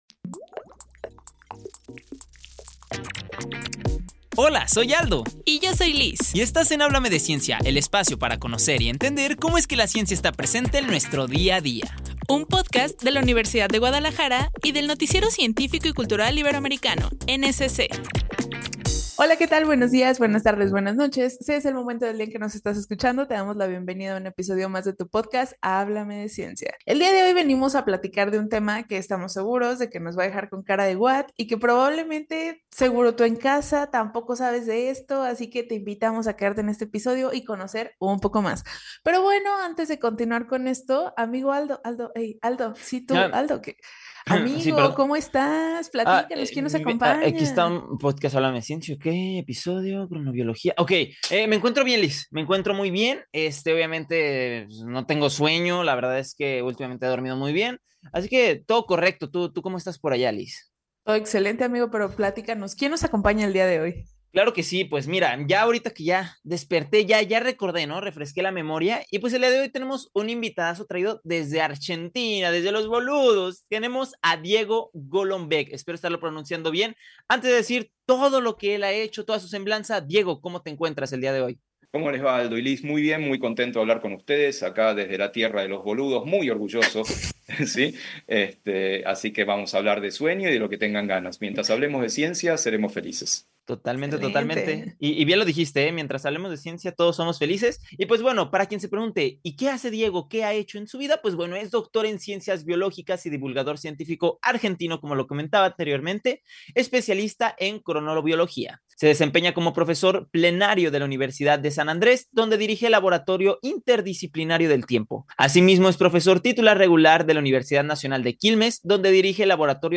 Conoce más sobre los ritmos del sueño y cómo impactan en nuestra vida diaria, con nuestro invitado especial Dr. Diego Golombeck, experto en cronobiología.